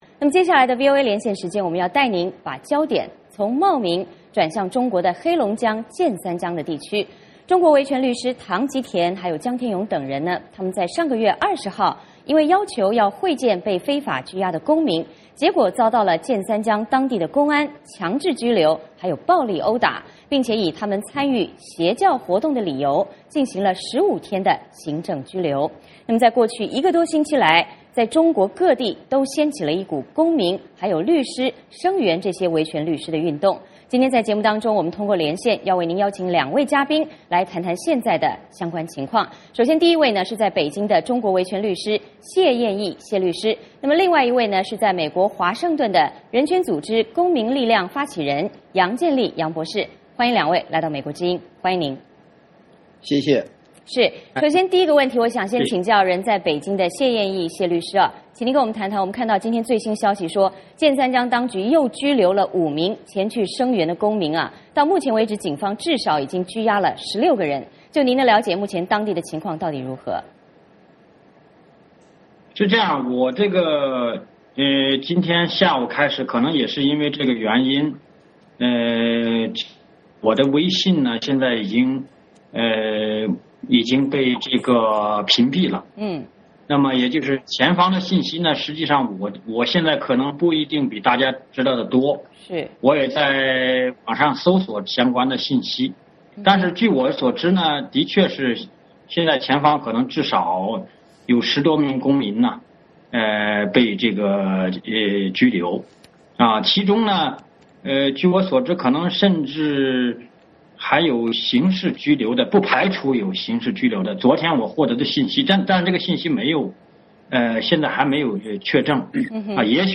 今天节目中我们通过连线请两位来宾谈谈有关情况。